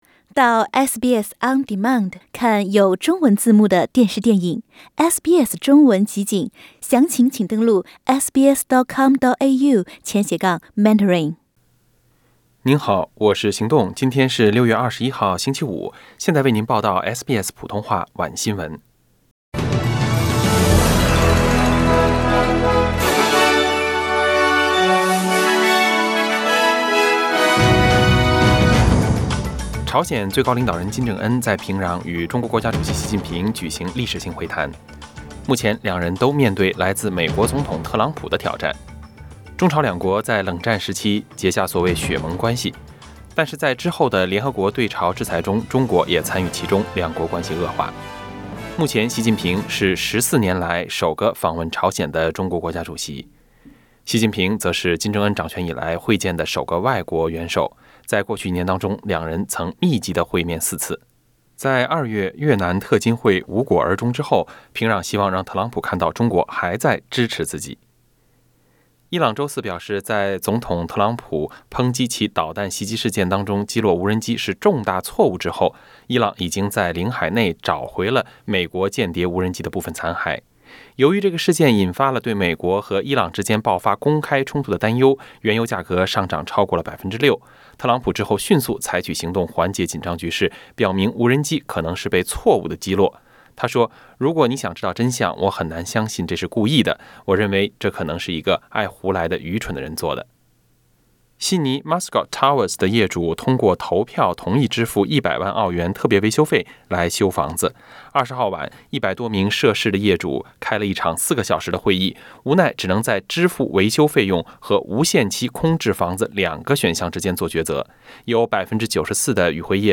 SBS晚新聞 （6月21日）